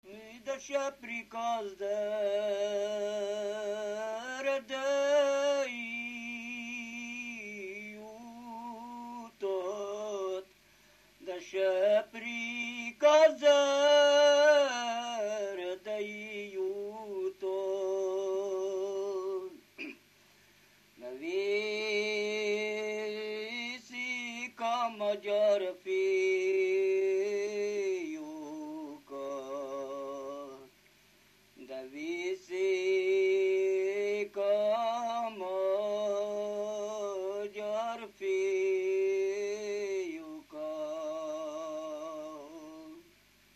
Erdély - Kolozs vm. - Visa
ének
Műfaj: Katonakísérő
Stílus: 3. Pszalmodizáló stílusú dallamok
Szótagszám: 8.8.8.8
Kadencia: 4 (b3) VII 1